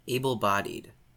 Ääntäminen
US : IPA : /ˈeɪ.bl̩ˌbɑ.did/